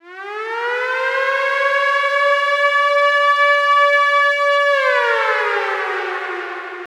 Metro Ambient Siren .wav